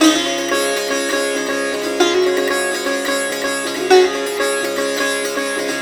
SITAR GRV 16.wav